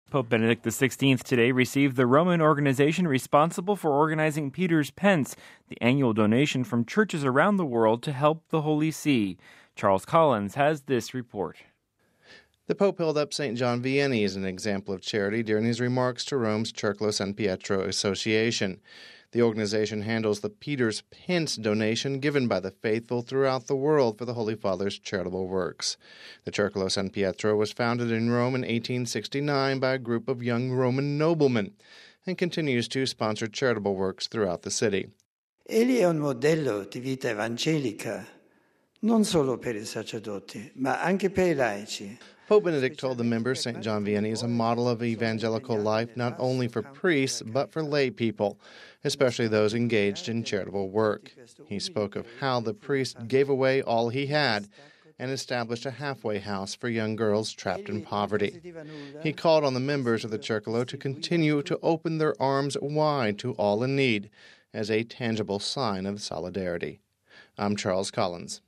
(26 June 10 - RV) Pope Benedict today received the Roman organization responsible for organizing Peter’s Pence, the annual donation from churches around the world to help the Holy See. We have this report: